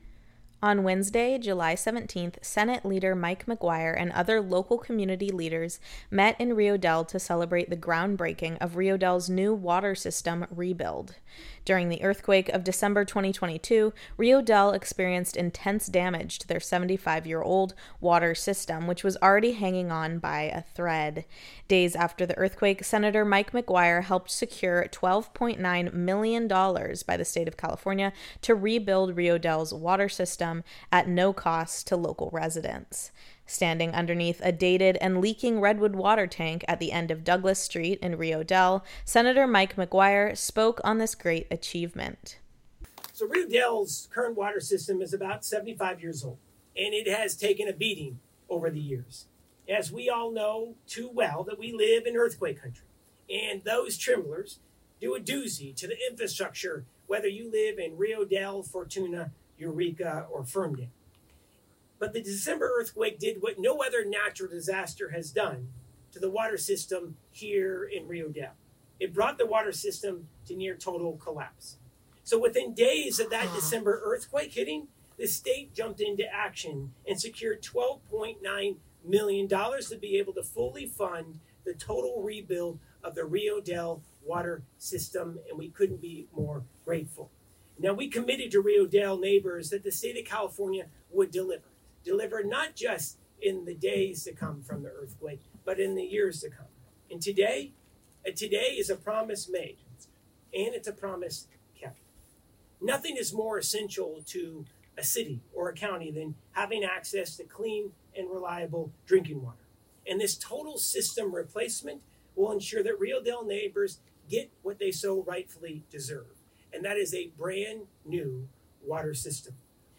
On Wednesday, July 17th, Senate Leader Mike McGuire and other local community leaders met in Rio Dell to Celebrate the Groundbreaking of Rio Dell’s new Water System Rebuild.
Days after the earthquake, Sen. McGuire helped secure a $12.9 million dollar investment by the State of California to rebuild Rio Dells water system, at no cost to local residents. Standing underneath a dated and leaking redwood water tank at the end of Douglas St in Rio Dell, Senator McGuire spoke on this great achievement.